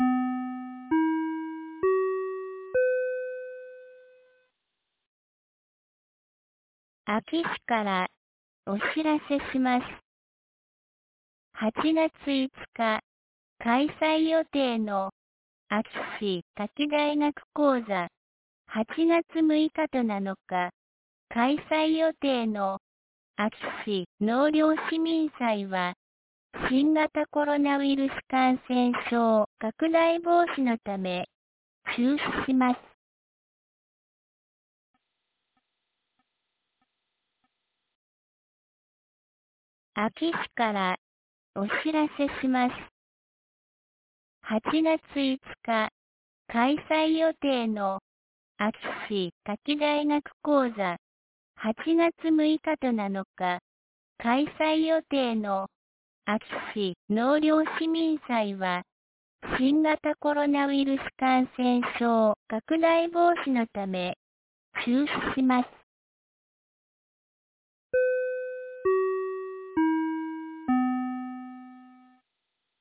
2022年08月03日 17時11分に、安芸市より全地区へ放送がありました。
放送音声